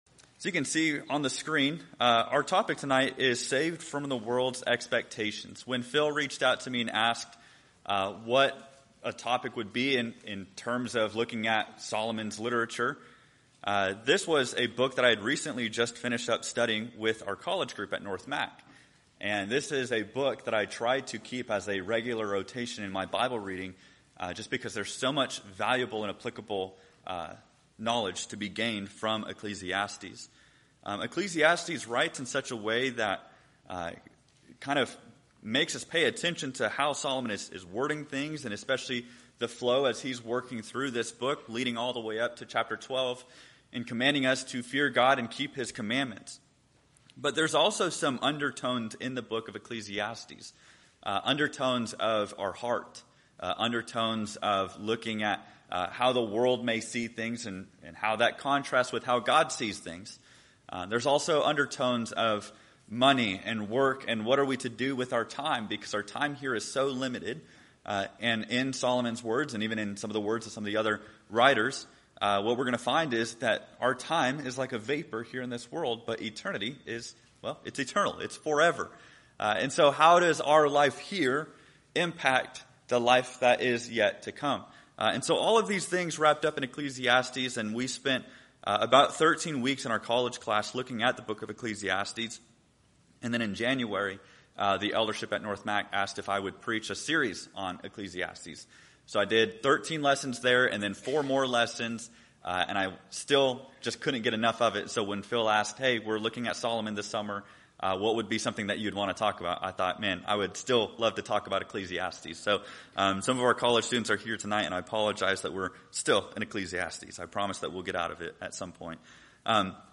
This lesson comes from the book of Ecclesiastes—a book I keep in regular rotation in my Bible reading because of the valuable and practical wisdom it contains.